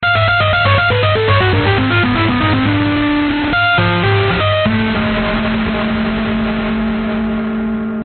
描述：电吉他声音120bpm作曲家
Tag: 作曲家 电动 电子 吉他 里夫